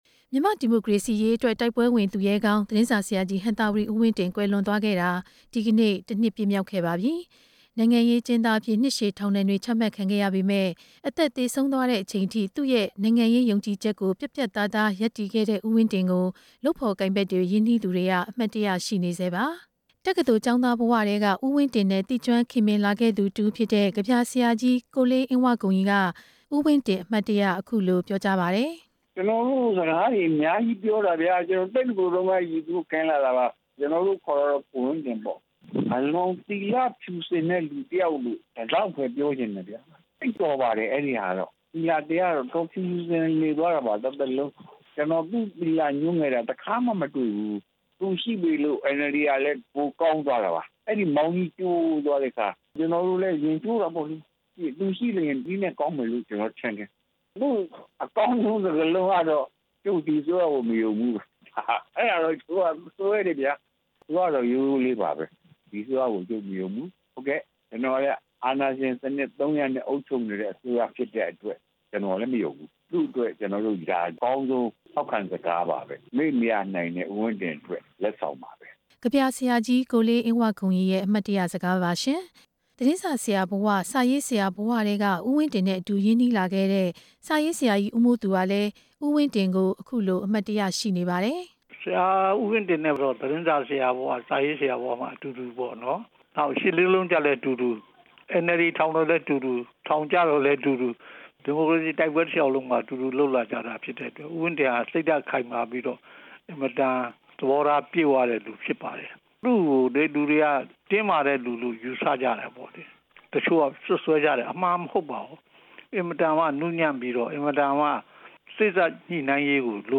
လုပ်ဖော်ကိုင်ဖက်တွေရဲ့ အမှတ်တရစကားများ နားထောင်ရန်